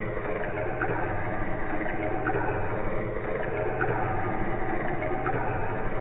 WATER:
water.ogg